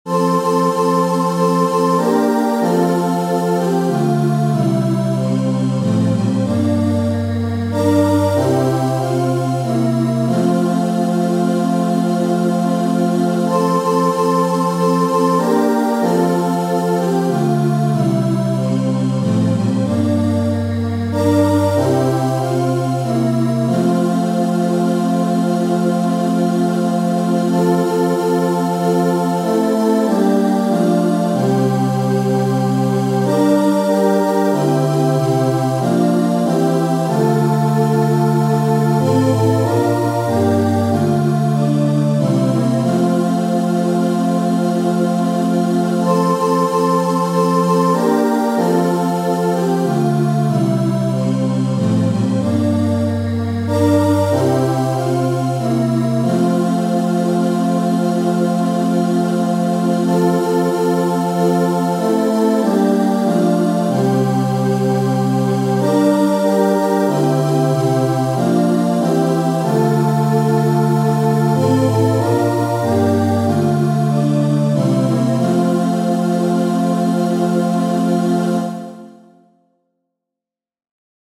Chants de Méditation Téléchargé par